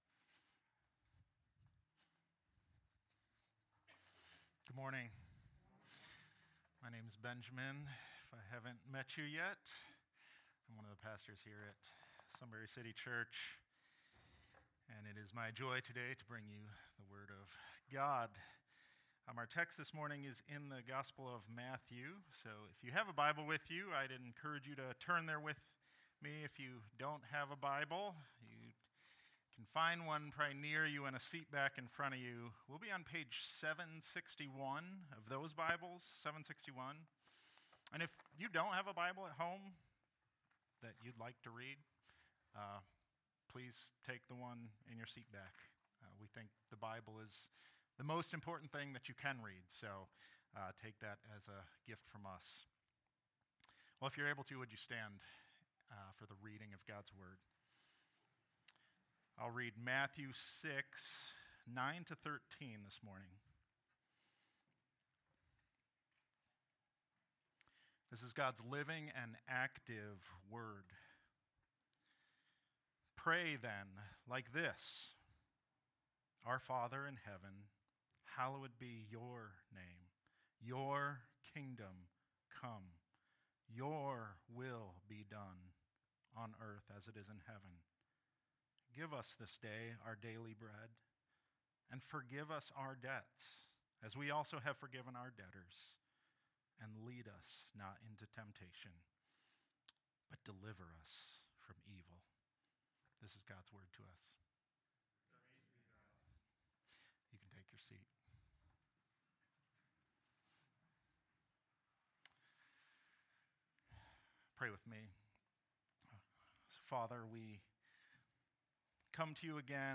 SERMONS | Sunbury City Church